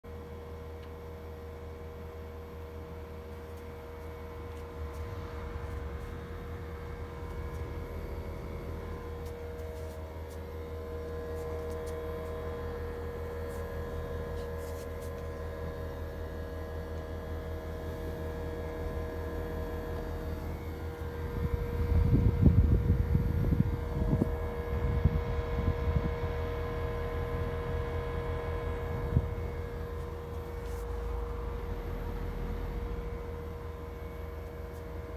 Bruit anormal de grincement du groupe extérieur Mitsubishi - Conseils Forum Dépannage Climatiseurs
D'après le commercial et le technicien, cela pourrait provenir soit du ventilateur, soit du compresseur, mais avec une préférence pour le compresseur.
Bruit groupe extérieur Mitsubishi 2
bruit-groupe-exterieur-mitsubishi-2.mp3